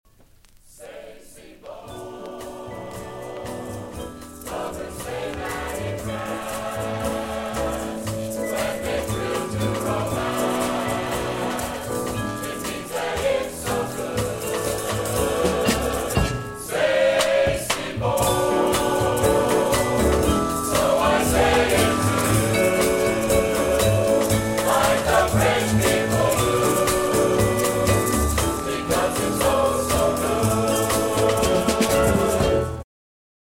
The guest musician was Clark Terry on the trumpet.
Jazz Chorus; Jazz Ensemble
alto saxophone
tenor saxophone
baritone saxophone
trombone
piano
vibes
percussion
bass
Jazz vocals